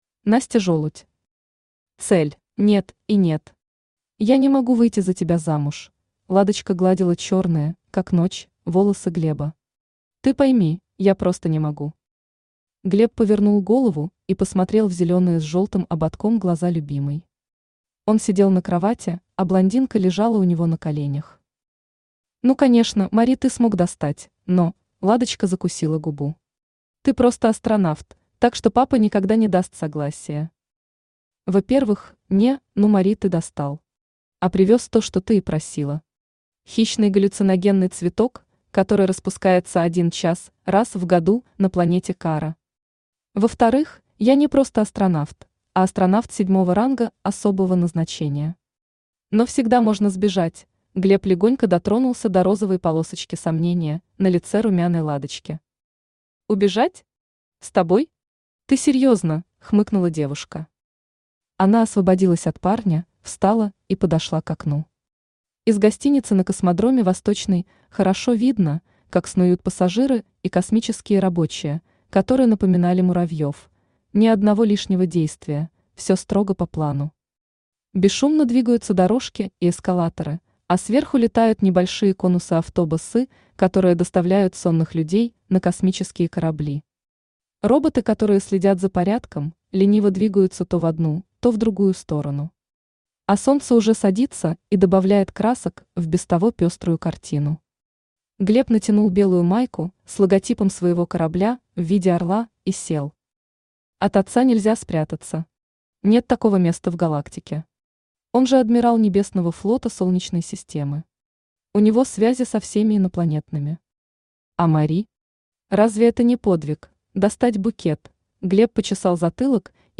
Aудиокнига Цель Автор Настя Жолудь Читает аудиокнигу Авточтец ЛитРес.